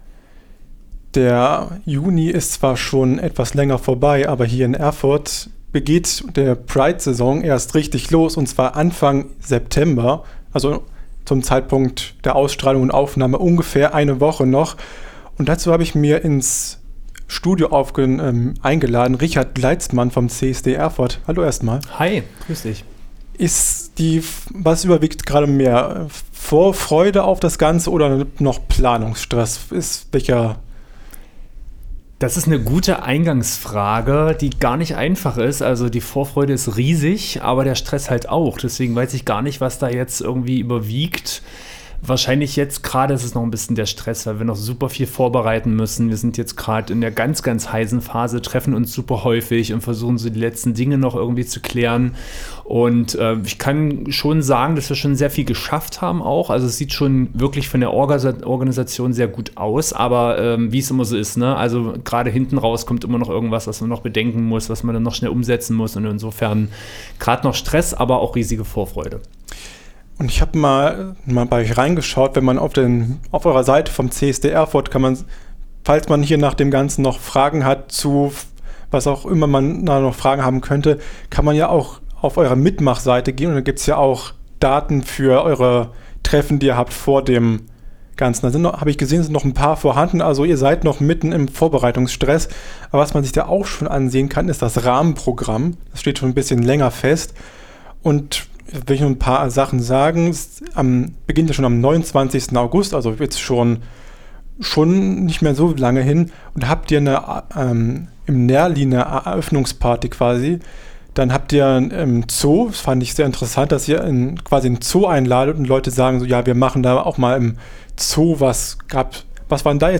CSD in schwierigen Zeiten - Interview mit